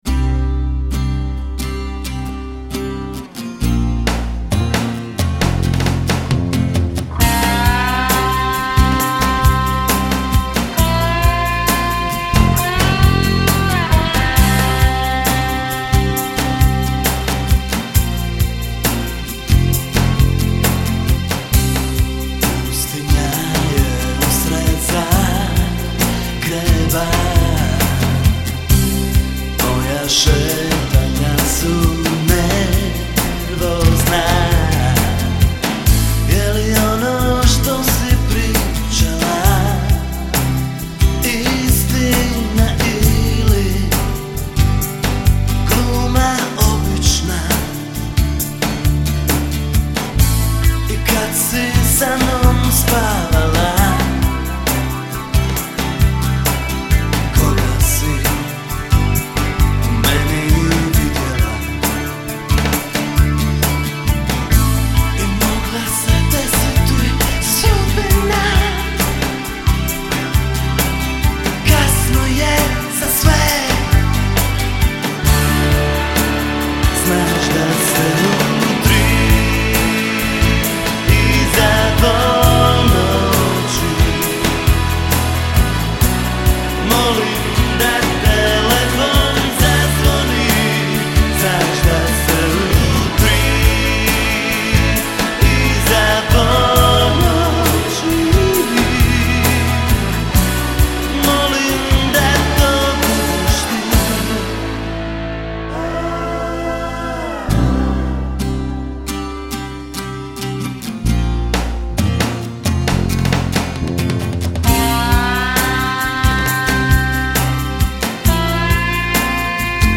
Rock - Pop